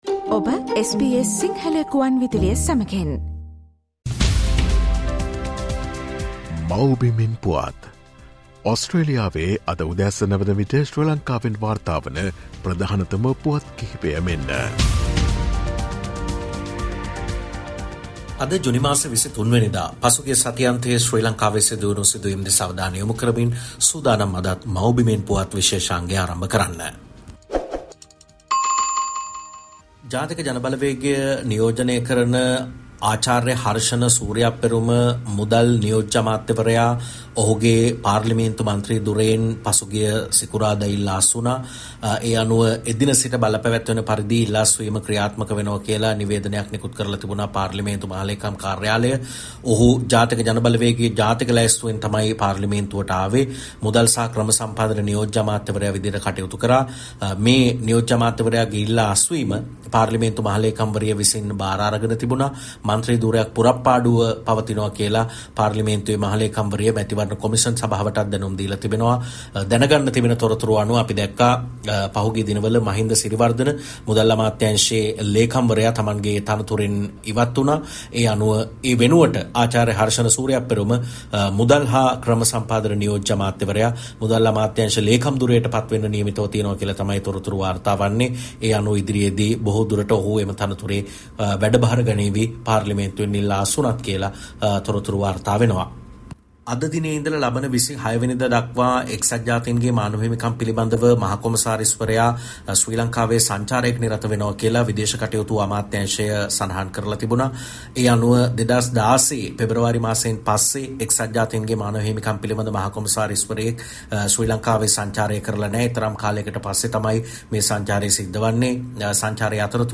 ශ්‍රී ලංකාවේ සිට වාර්තා කරයිි